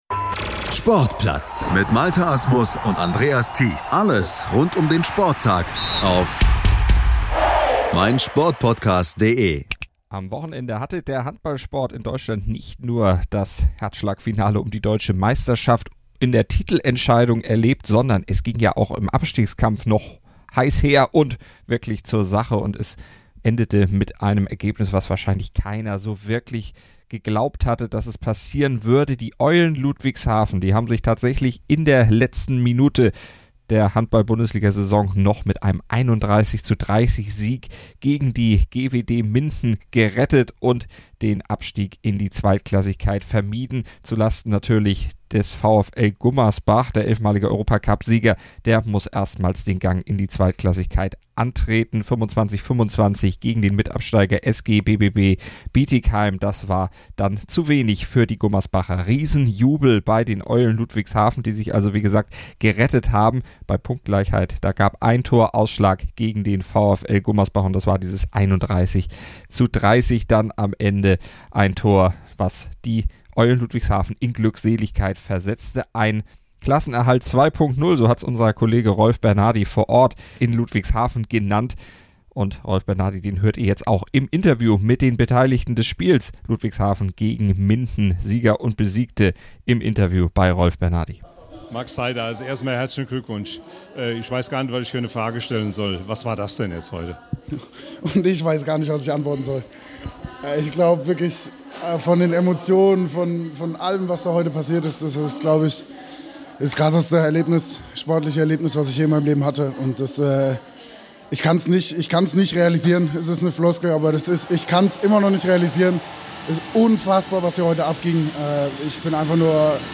vor Ort eingefangen und mit den glücklichen Weiter-Erstligisten gesprochen